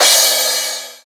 DJP_PERC_ (6).wav